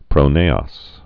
(prō-nāŏs)